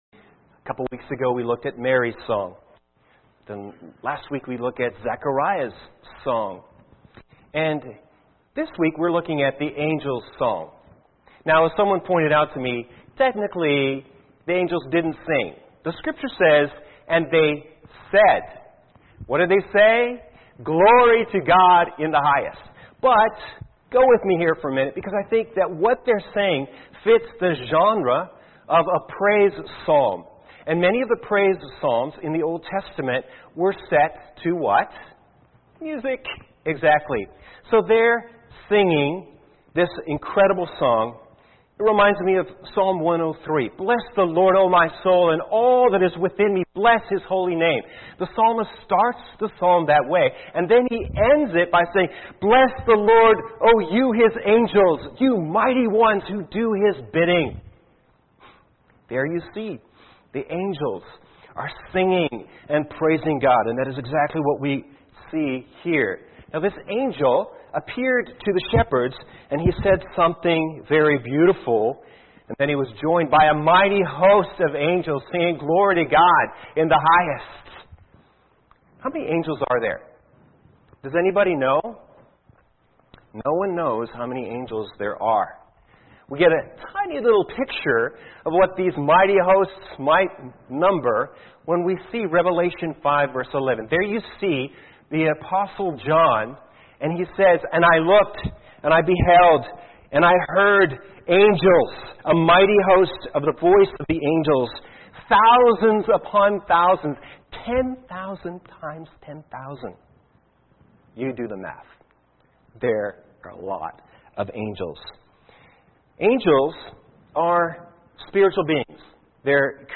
Sermon 12-24-17